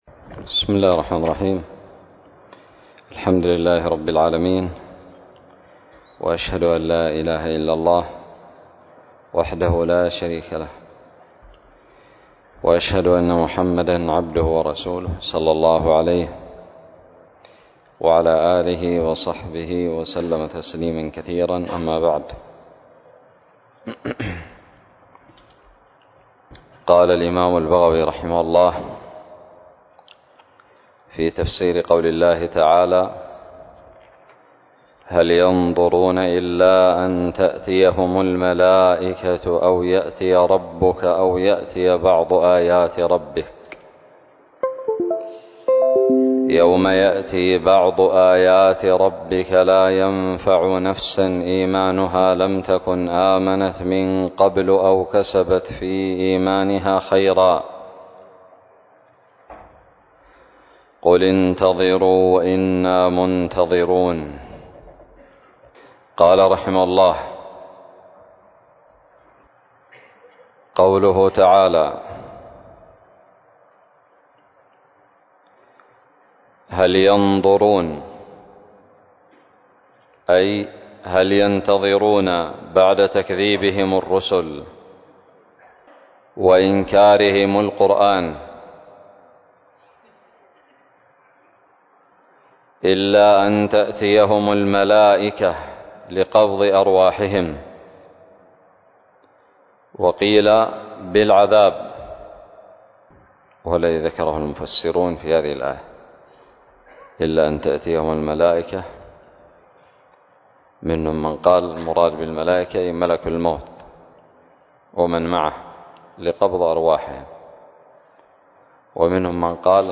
الدرس الثاني والخمسون من تفسير سورة الأنعام من تفسير البغوي
ألقيت بدار الحديث السلفية للعلوم الشرعية بالضالع